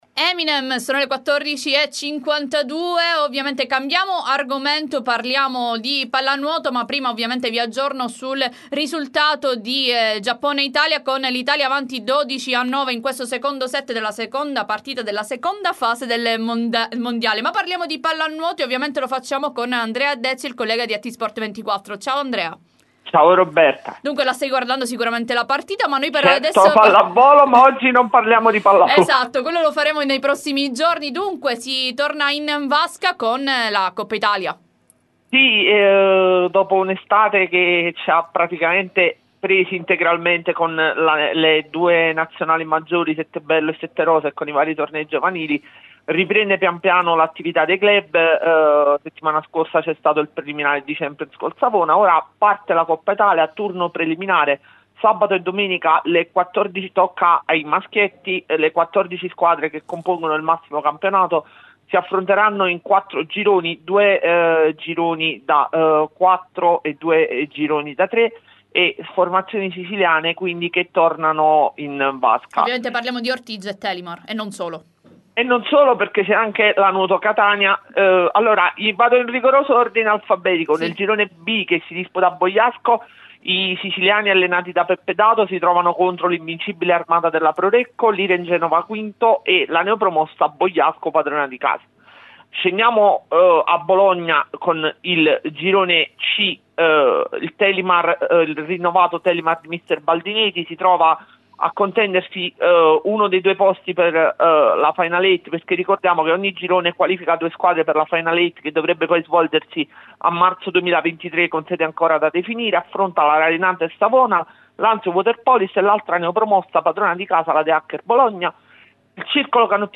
Time Sport intervista Palermo Calcio a 5